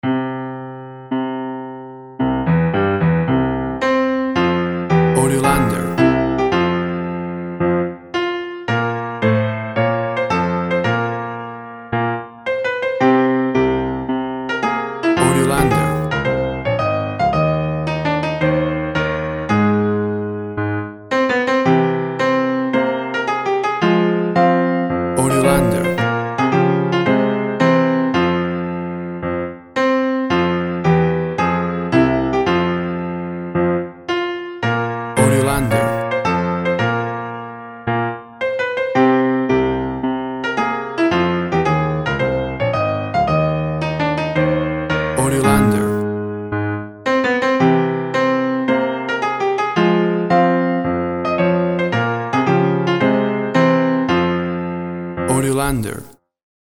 A beautiful and stunning version
played on a Grand Piano
Tempo (BPM) 90